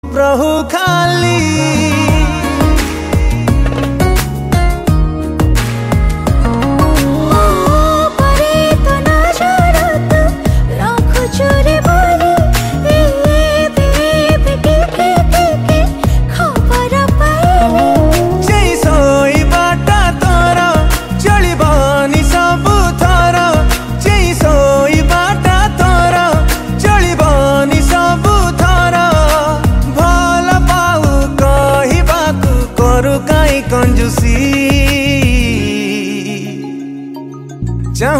Odia Ringtones
dance song